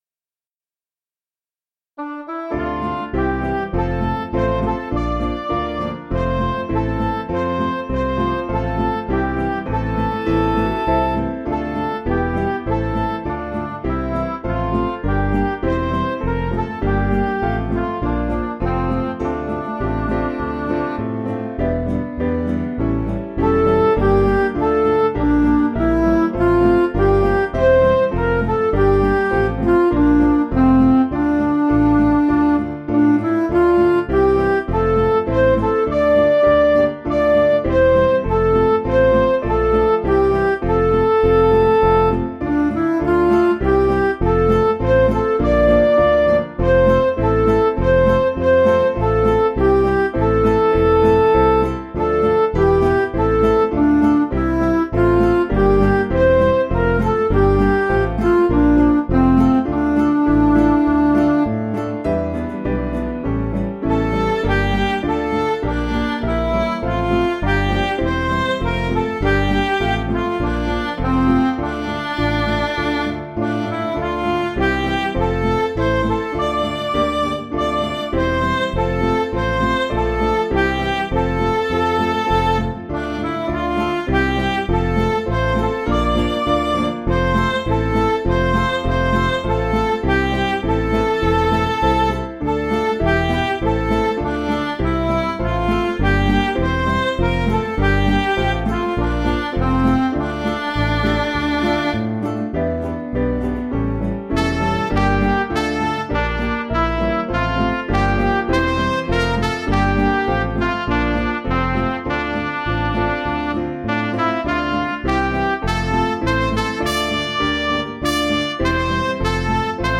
Piano & Instrumental
(CM)   3/Dm